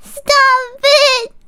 Worms speechbanks
Youllregretthat.wav